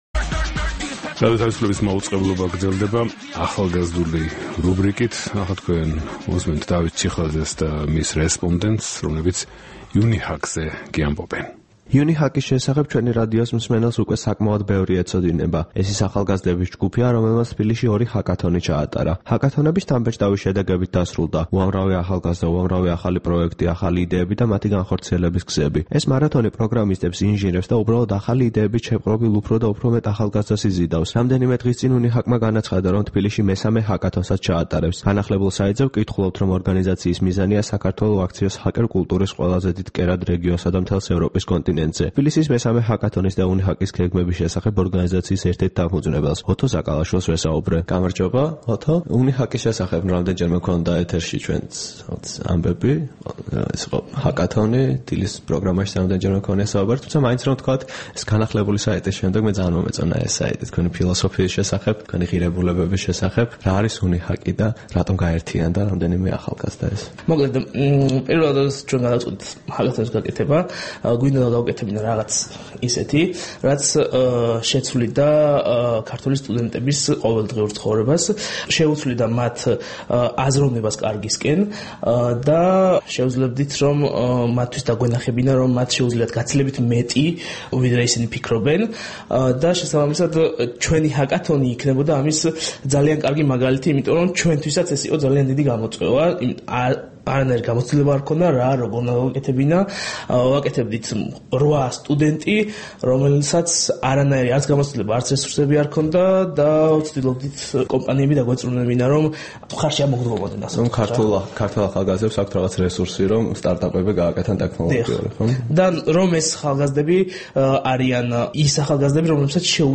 by რადიო თავისუფლება